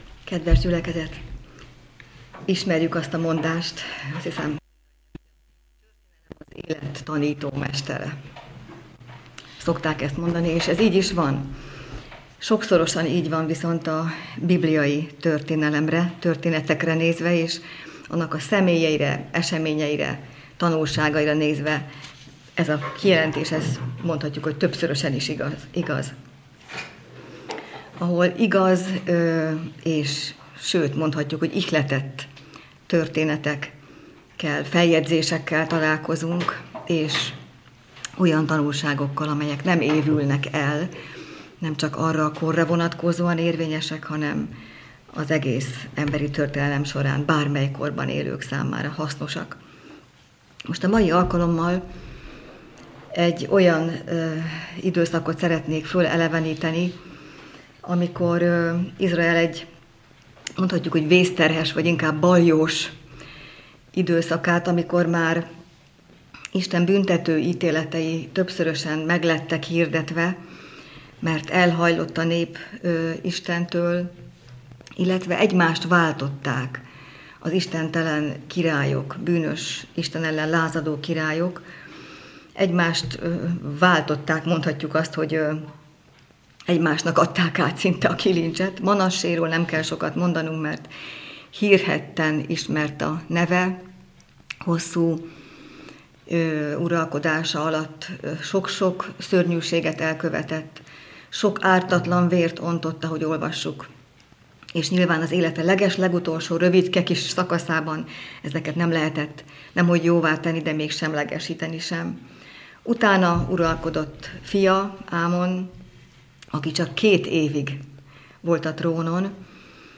Szombati igehirdetés